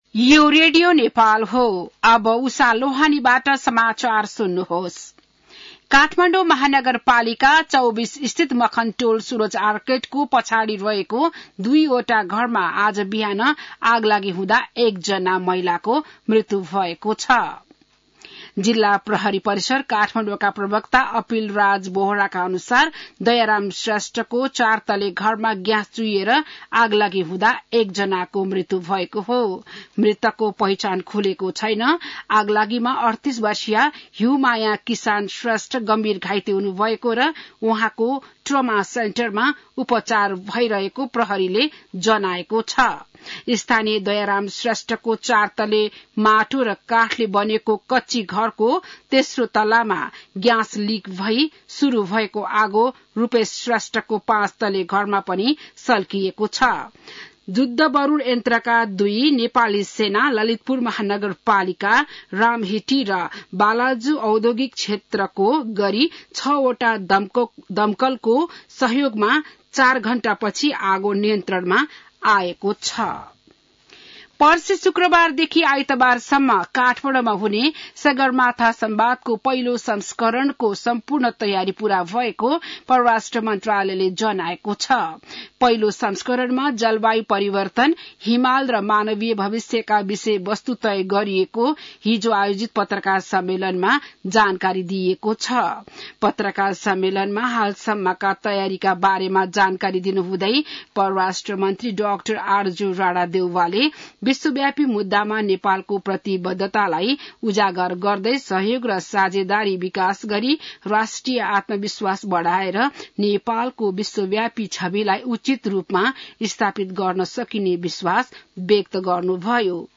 An online outlet of Nepal's national radio broadcaster
बिहान ११ बजेको नेपाली समाचार : ३१ वैशाख , २०८२